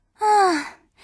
sigh.wav